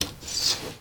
Door_open.L.wav